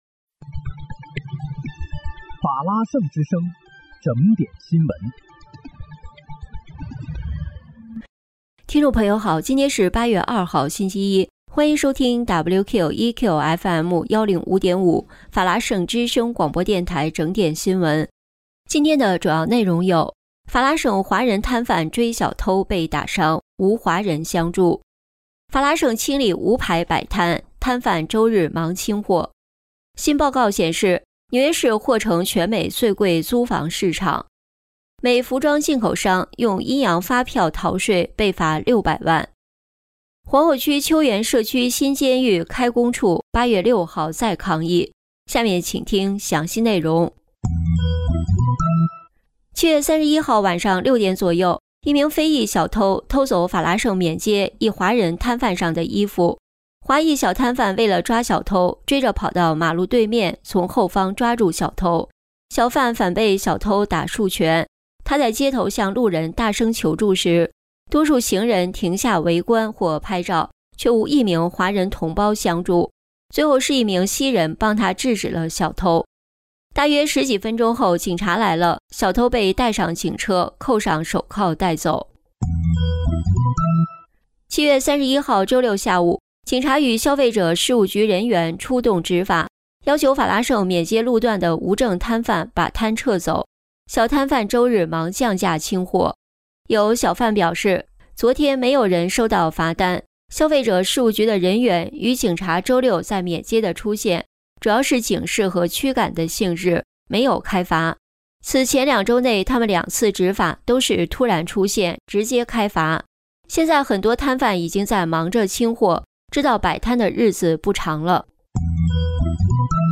8月2日（星期一）纽约整点新闻
听众朋友您好！今天是8月2号，星期一，欢迎收听WQEQFM105.5法拉盛之声广播电台整点新闻。